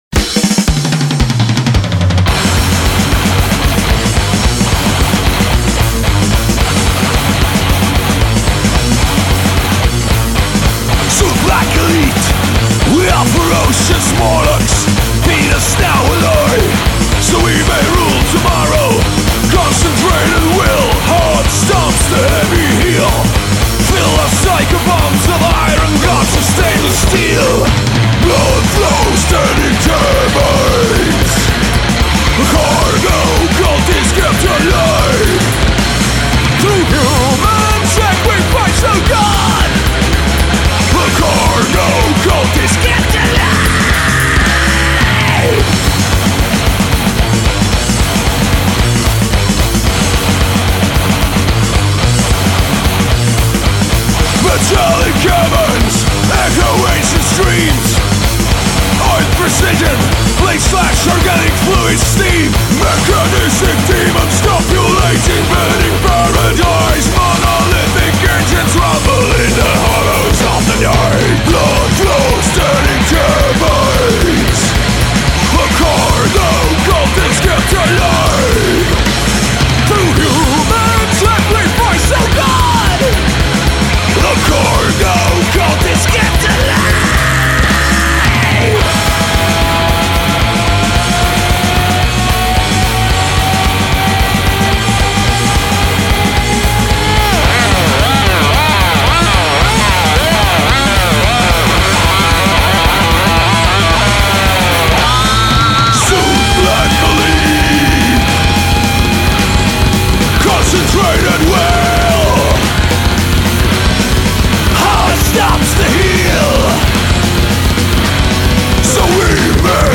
Vankumatu, äge, vaimukas